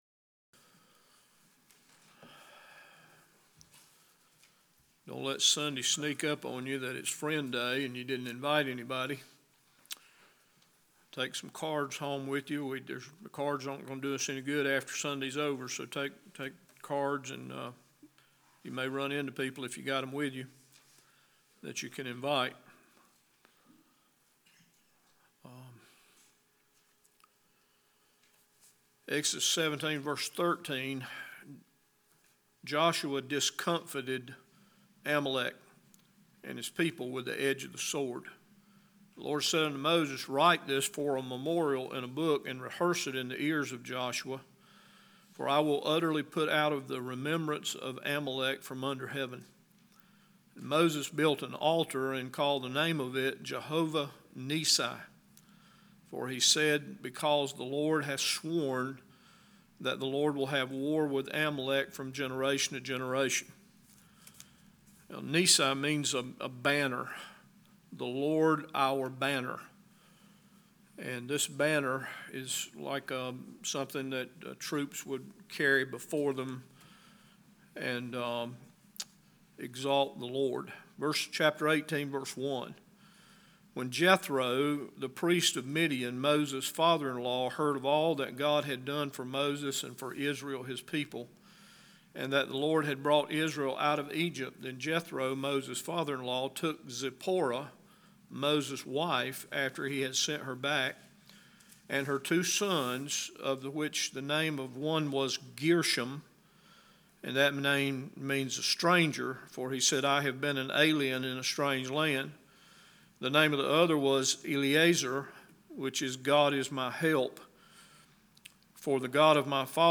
Today was Friend Day at Bible Baptist and we are thankful for dozens of friends who spent the morning with us.